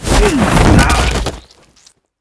tackle5.ogg